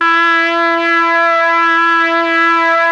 RED.BRASS 22.wav